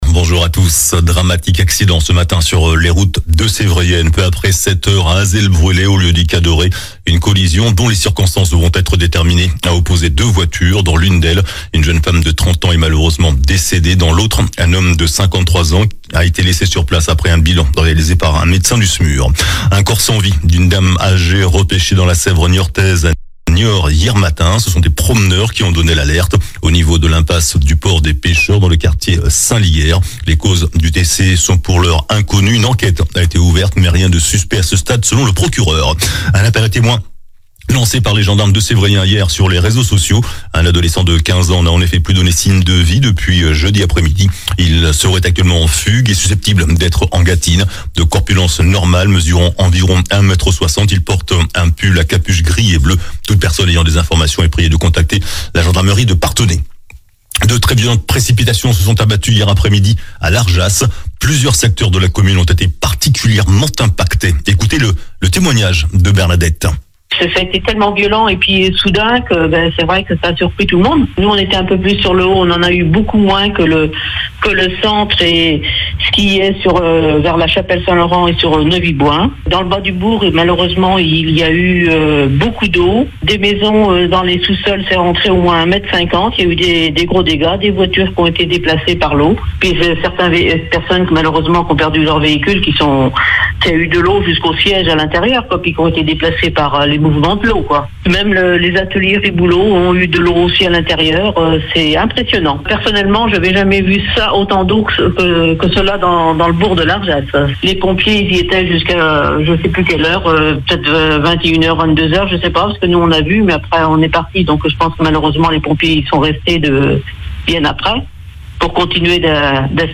JOURNAL DU SAMEDI 22 JUIN